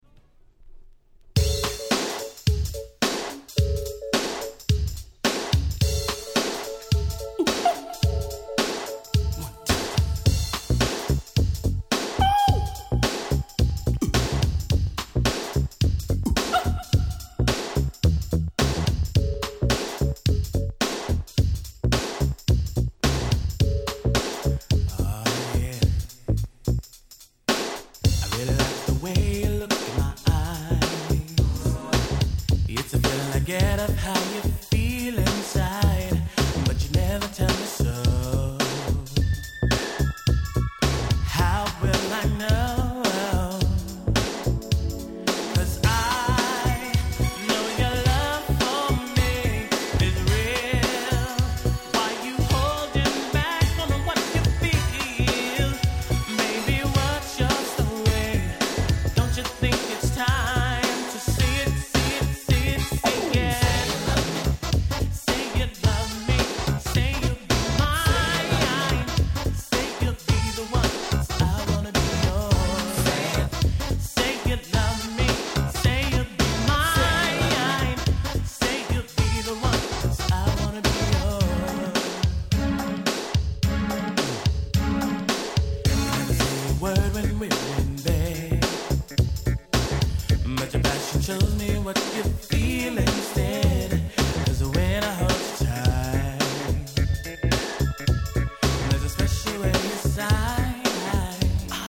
90' Nice New Jack Swing/R&B LP !!
シングルカットされたA-3を始め、ハネハネなNice New Jack Swing盛り沢山！